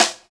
Index of /90_sSampleCDs/Roland L-CD701/SNR_Rim & Stick/SNR_Stik Modules
SNR THIN S0I.wav